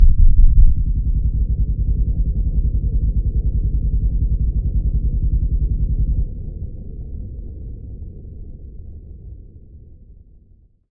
deafness_noring.ogg